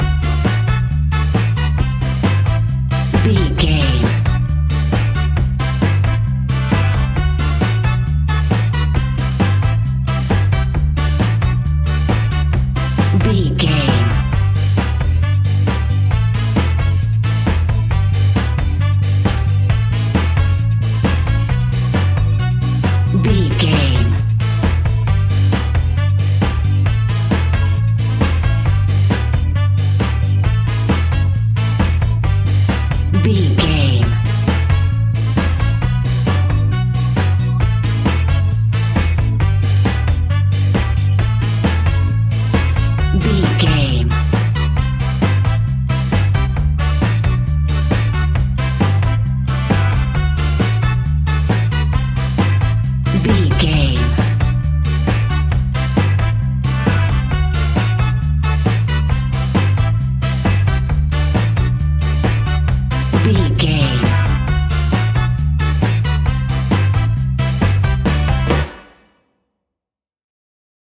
Classic reggae music with that skank bounce reggae feeling.
Aeolian/Minor
laid back
off beat
drums
skank guitar
hammond organ
percussion
horns